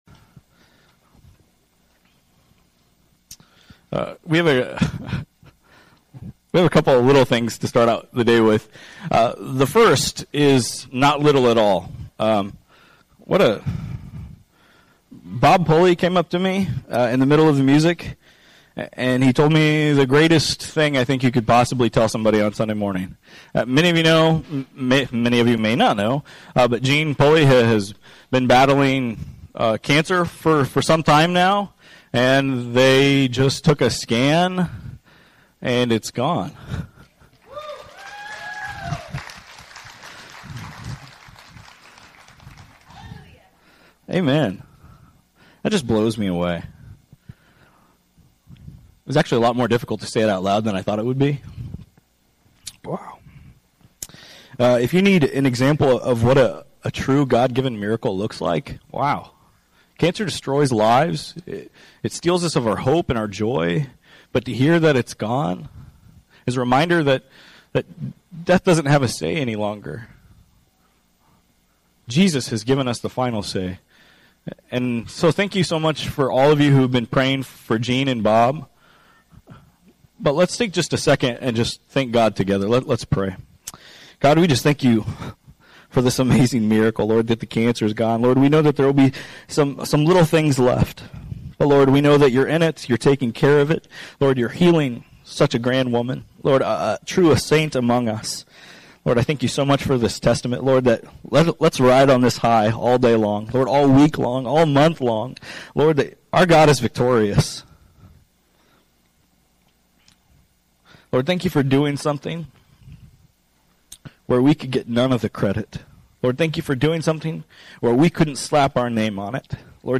preaches the final message of our Holy Generosity series looking at how God wants to use our Time, Talents, and Treasures!